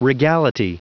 Prononciation du mot regality en anglais (fichier audio)
Prononciation du mot : regality